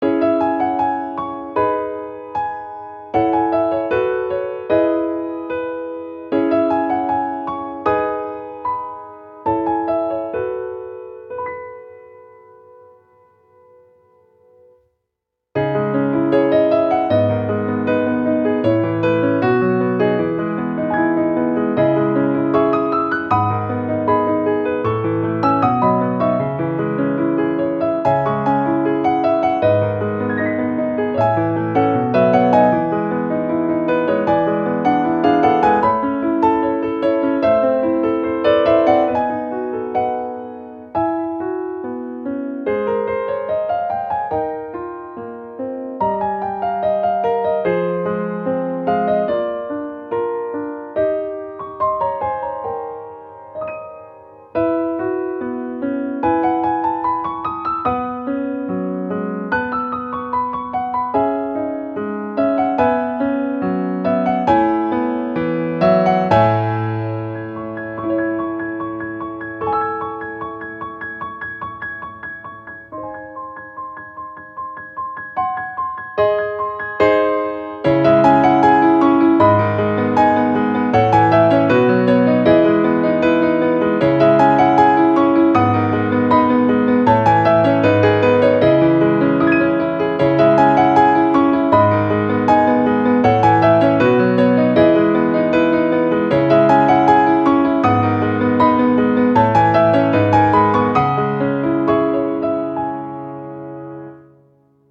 -oggをループ化-   可憐 儚い 2:10 mp3